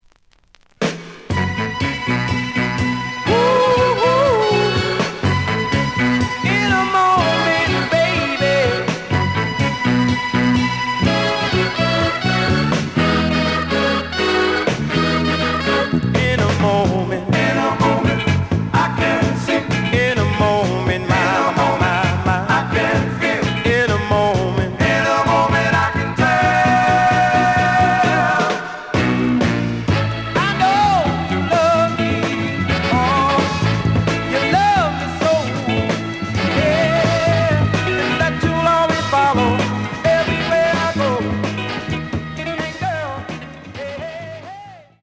The audio sample is recorded from the actual item.
●Genre: Soul, 60's Soul
Slight noise on A side.)